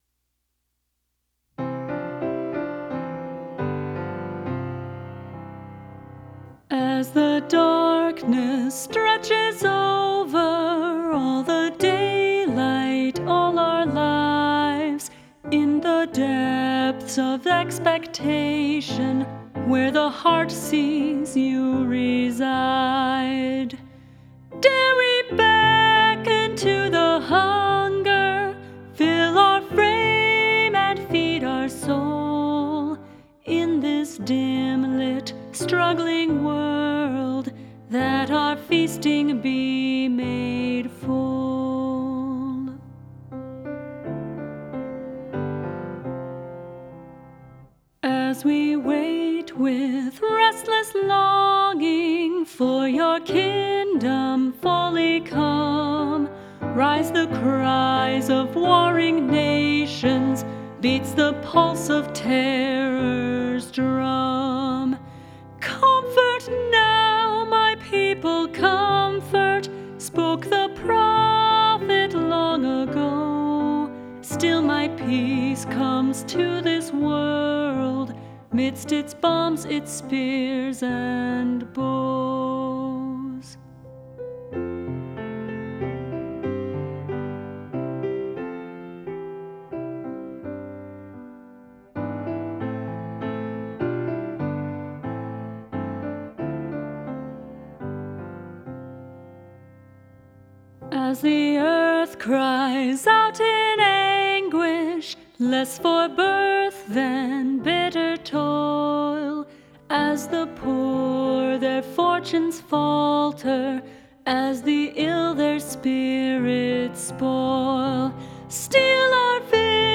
give a hauntingly beautiful rendition